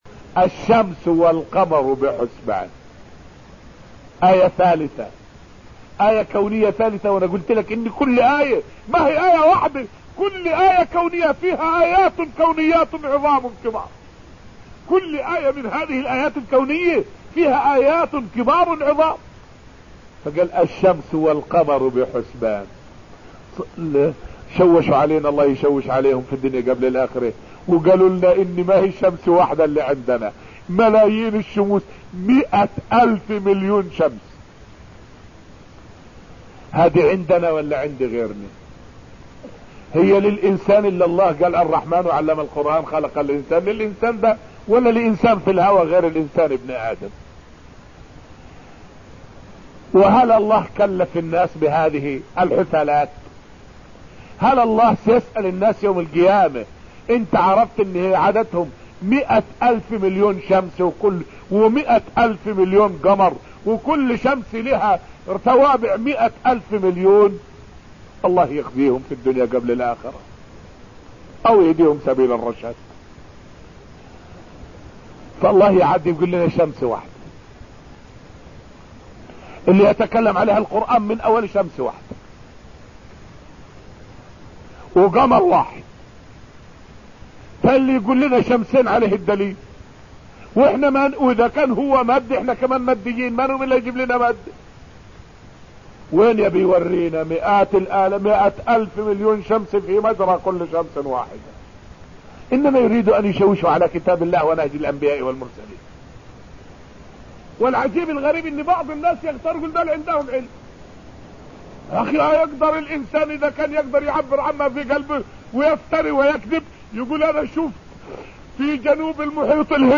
فائدة من الدرس السابع من دروس تفسير سورة الرحمن والتي ألقيت في المسجد النبوي الشريف حول من آيات الله في مخلوقاته اختلاف الطبائع مع اتحاد الشكل.